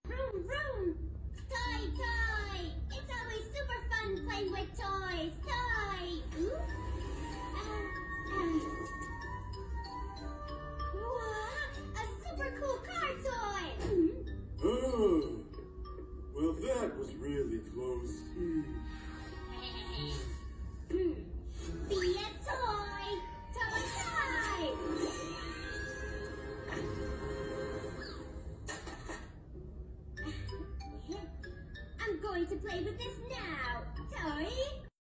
Español (Latinoamericano) voice actor